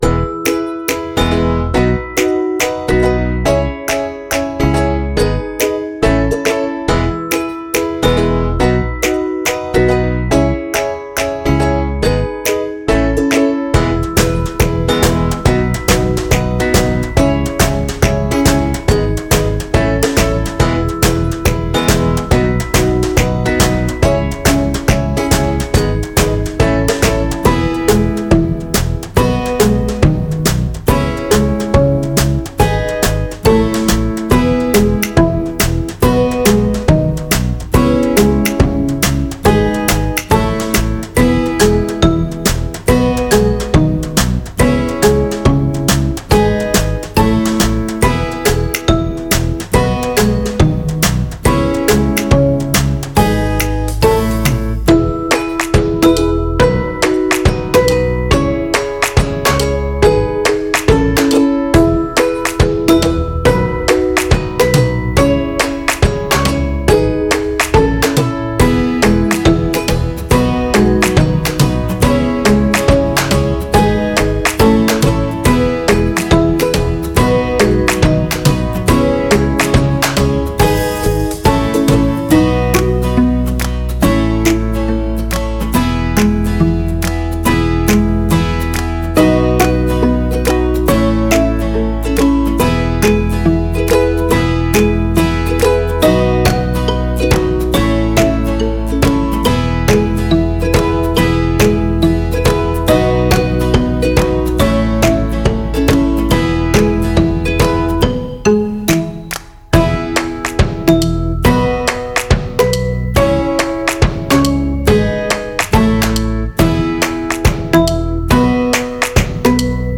это яркая и жизнеутверждающая песня в жанре поп